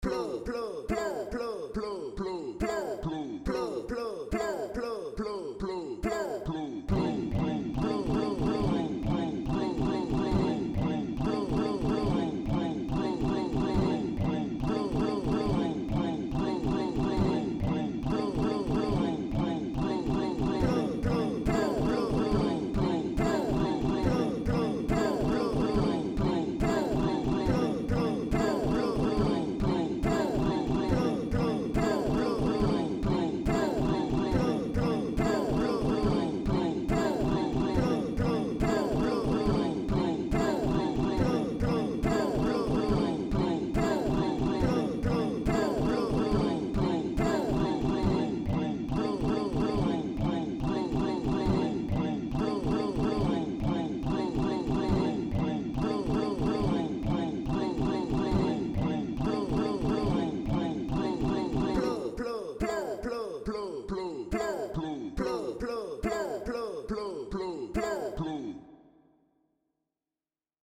Experimental
Loop
* Contains third-party samples.